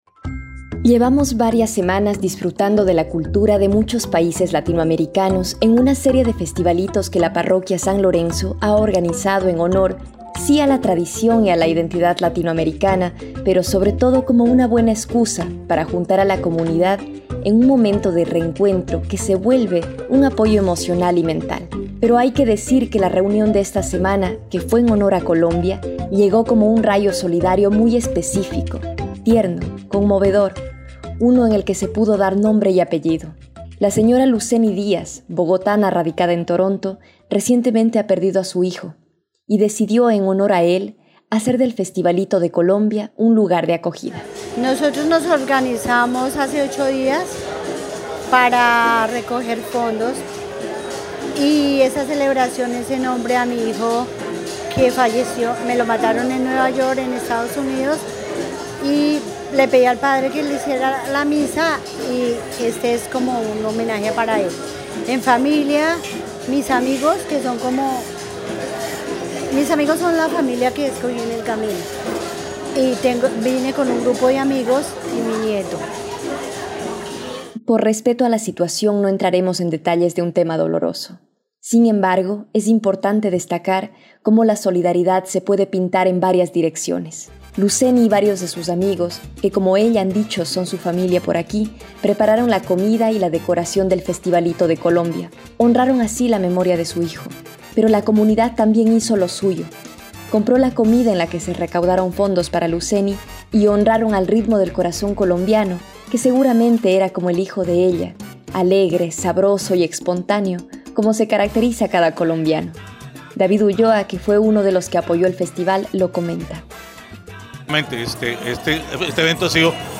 Reportaje-festivalito-de-Colombia.mp3